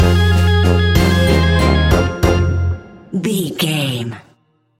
Aeolian/Minor
ominous
haunting
eerie
brass
electric organ
drums
synthesiser
strings
creepy
spooky